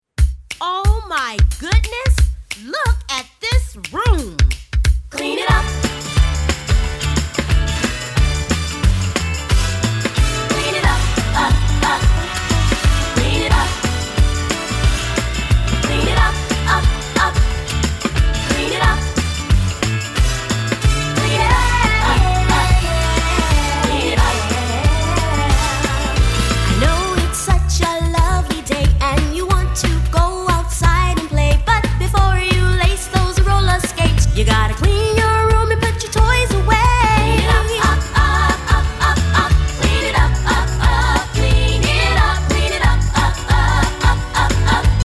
doo-wop and 80s R&B inspired
All tracks except Radio Edits include scripted dialogue.